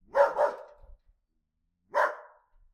dog.ogg